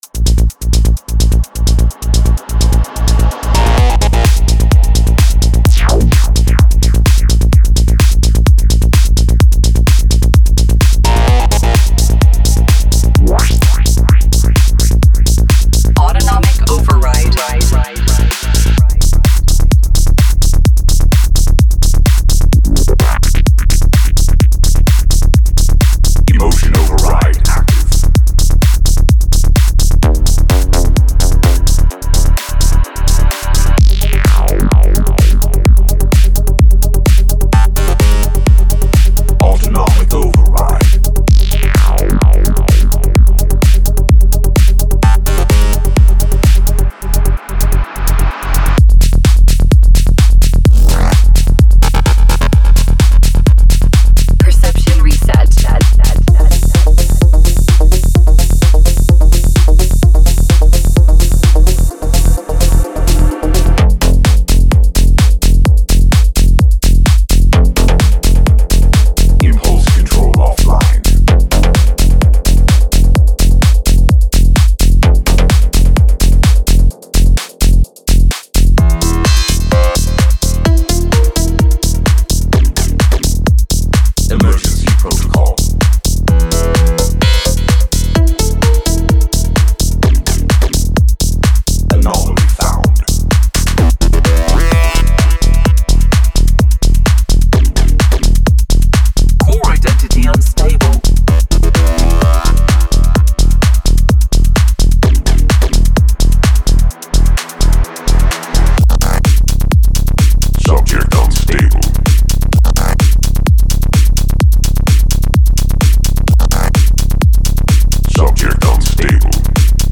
サウンドはフロー、動き、エネルギーに最適化されており、アレンジを素早く効率的に構築するのに理想的です。
優れたサイケデリックシンセと深みのあるアトモスフィアが、見事なテクノサウンドスケープの中に収められています。
デモサウンドはコチラ↓
Genre:Psy Trance
24Bit 44.1KHZ